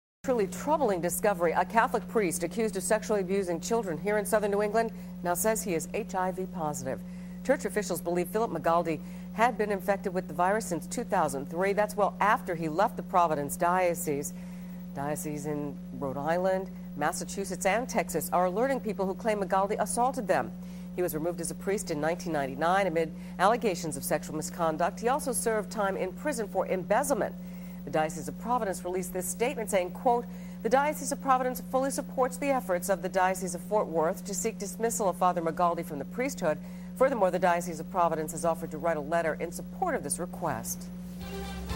Priest Accused of Sexual Assault is HIV Positive (news clip)